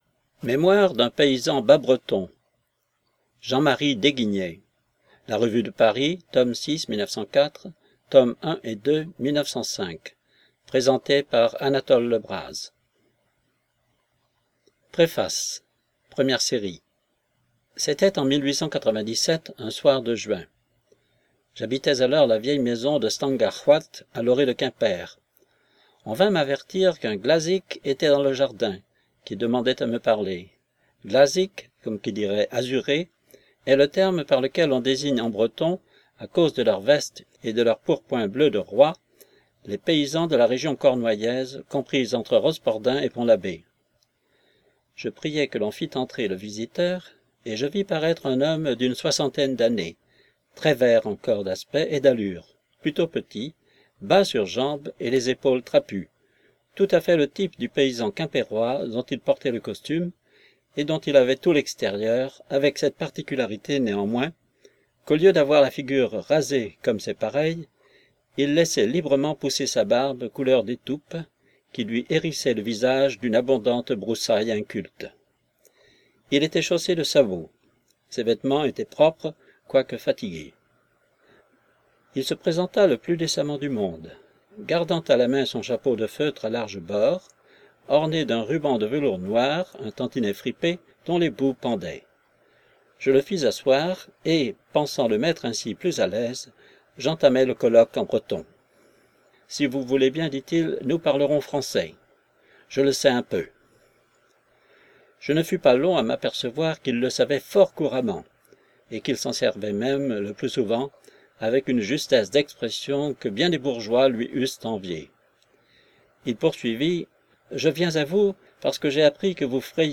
DÉGUIGNET Jean-Marie – Livre Audio